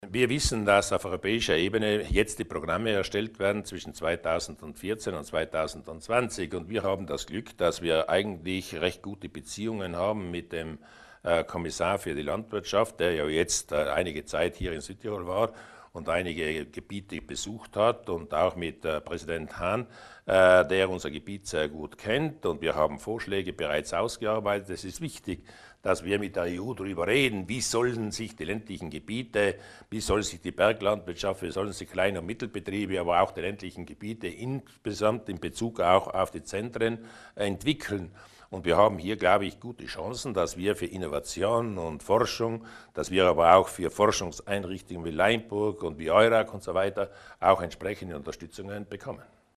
Auf diese vier Kürzel lässt sich auch die Ausrichtung der Europapolitik der Landesregierung bringen, die Landeshauptmann Luis Durnwalder heute (16. August) bei seiner Sommerpressekonferenz in Pfalzen vorstellte.